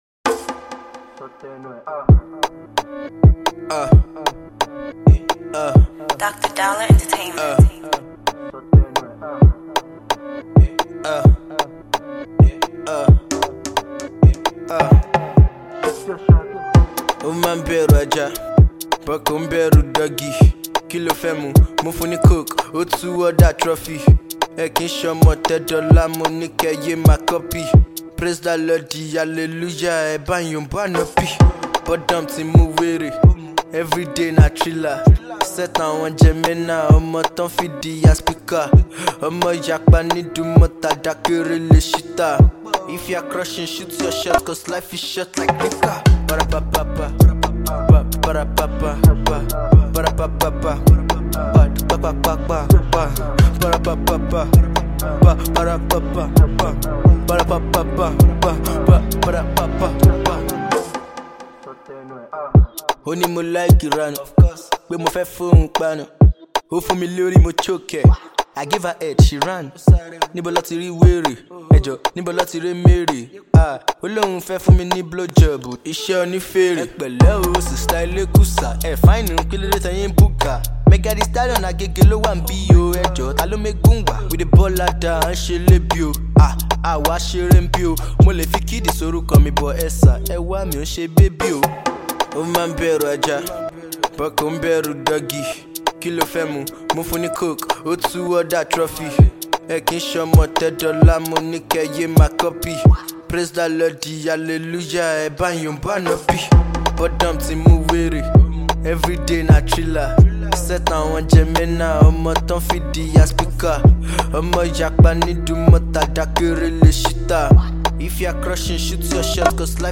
The rapper and singer